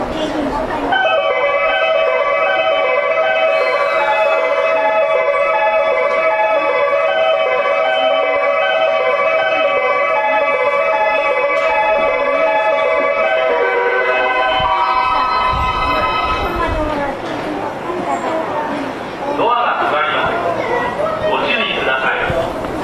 2005年末に自動放送の声と言い回しが変更され、